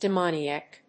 音節de・mo・ni・ac 発音記号・読み方
/dɪmóʊni`æk(米国英語)/